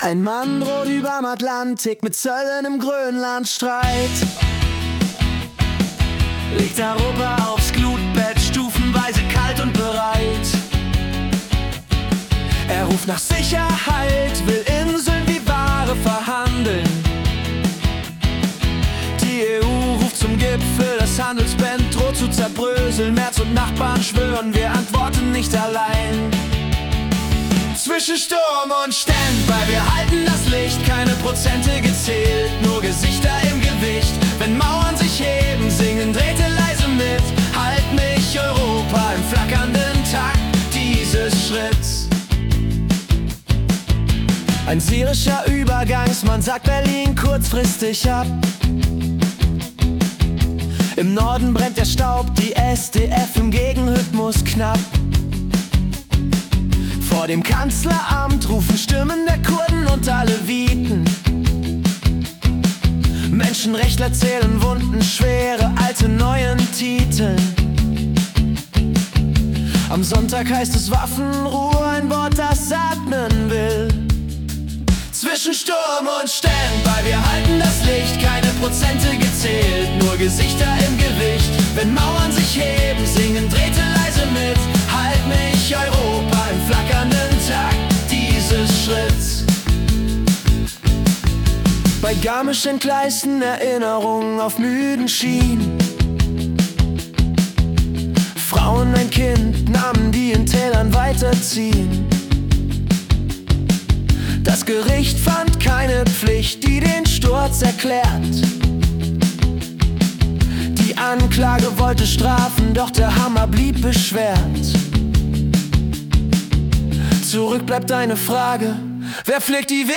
Die Nachrichten vom 20. Januar 2026 als Singer-Songwriter-Song interpretiert.